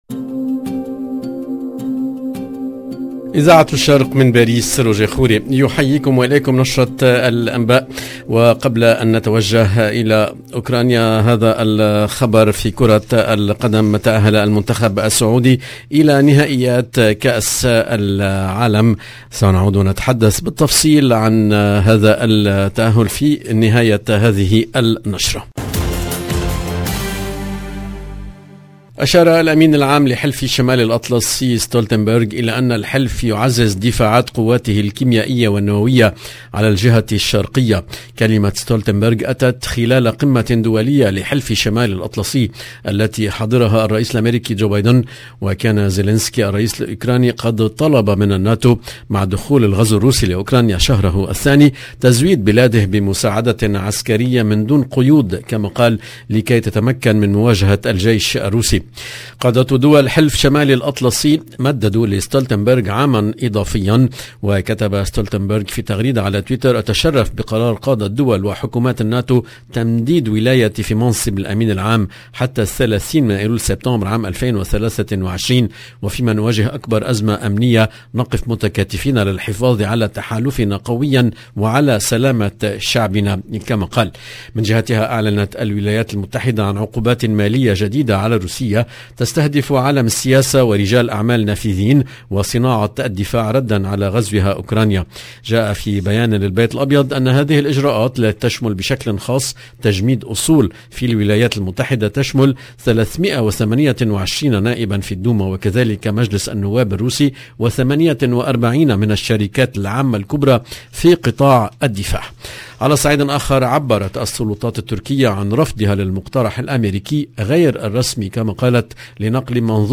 EDITION DU JOURNAL DU SOIR EN LANGUE ARABE DU 24/3/2022